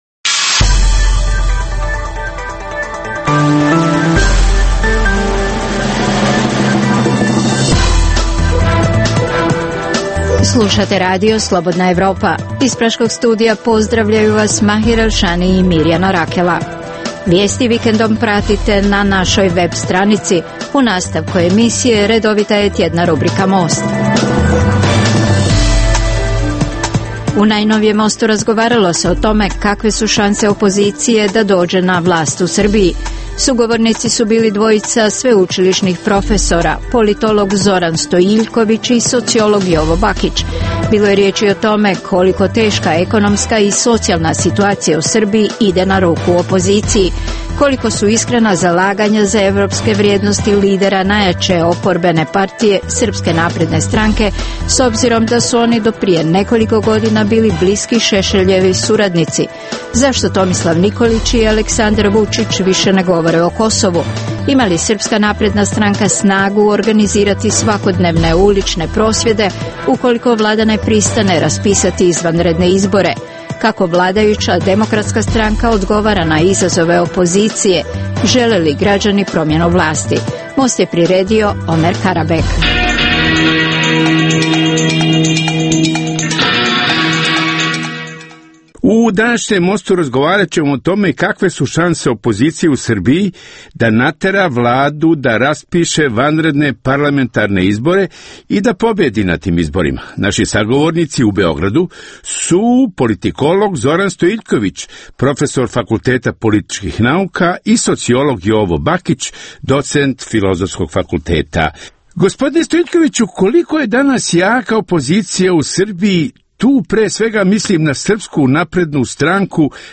u kojem ugledni sagovornici iz regiona diskutuju o aktuelnim temama. Drugi dio emisije čini program "Pred licem pravde" o suđenjima za ratne zločine na prostoru bivše Jugoslavije, koji priređujemo u saradnji sa Institutom za ratno i mirnodopsko izvještavanje iz Londona.